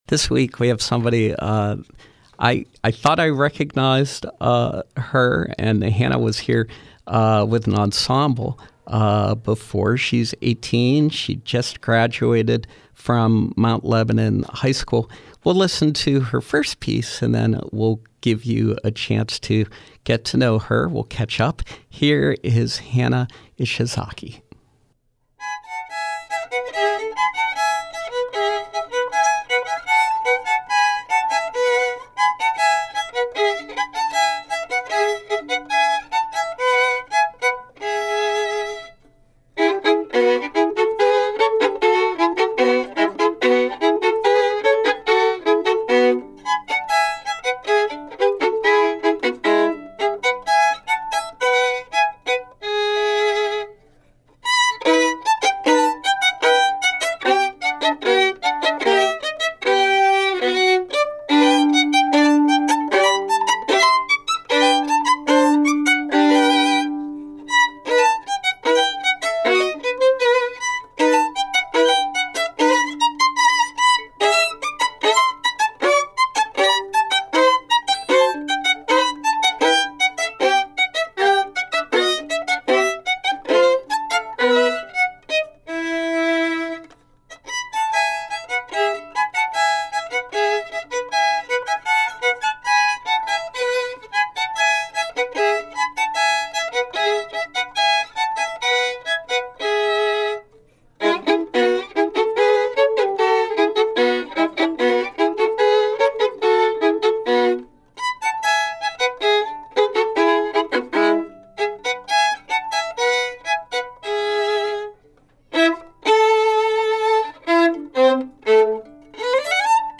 with selections on violin.